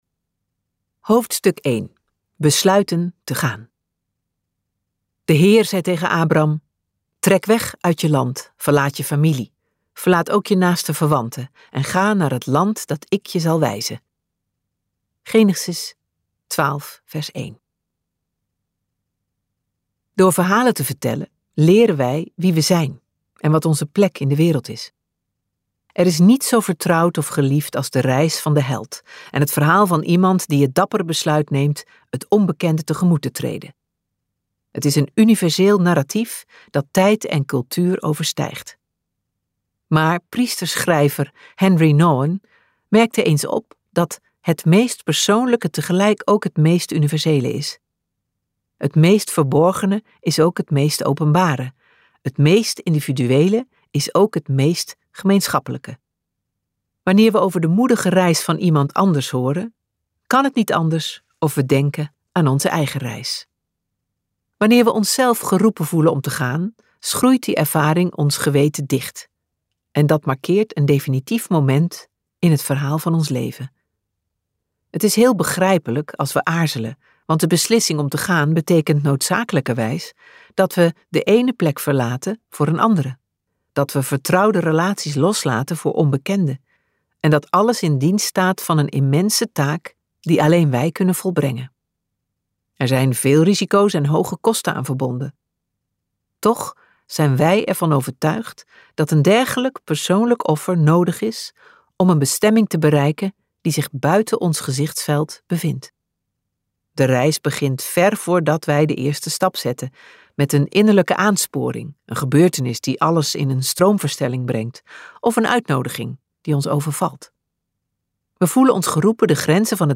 Luisterboek : MP3 download